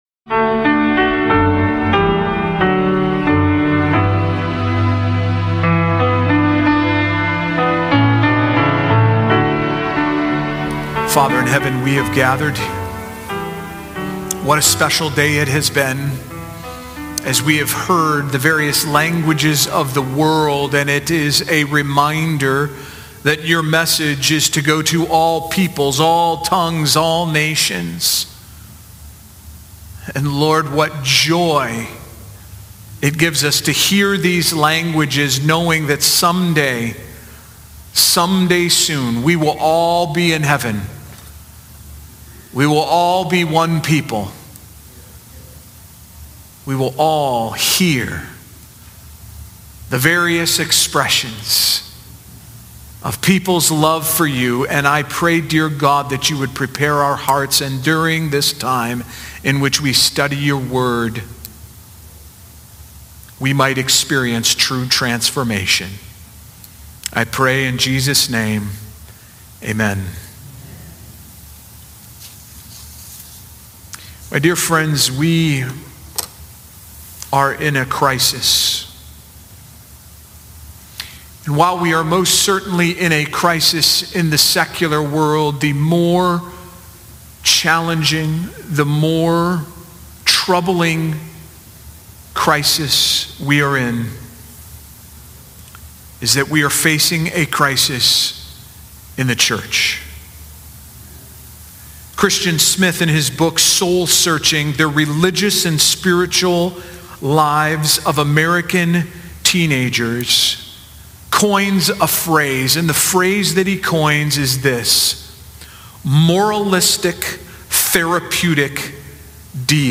How is modern culture shaping faith, and what can we learn from Jonah’s story of disobedience, trials, and redemption? This thought-provoking sermon explores the impact of moralistic therapeutic deism, the need for theological dialogue, and God’s perfect balance of justice and mercy, offering a powerful call to action for believers.